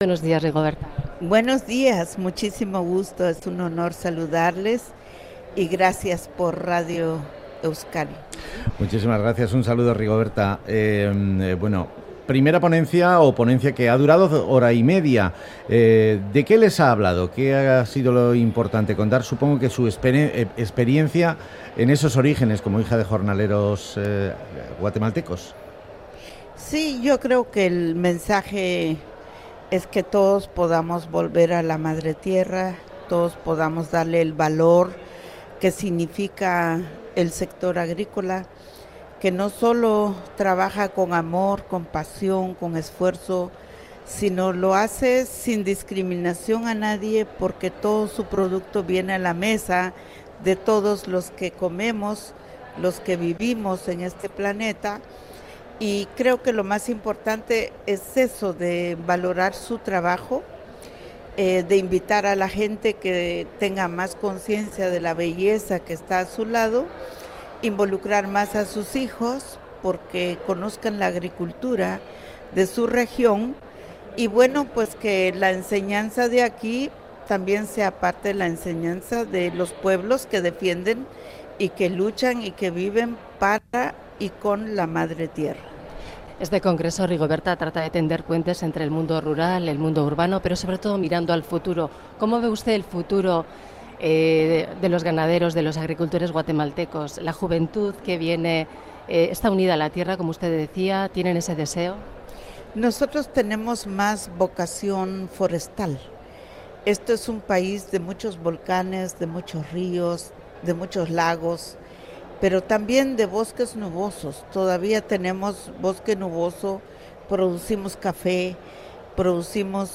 Audio: Entrevista a Rigoberta Menchú, Premio Nobel de la Paz en 1992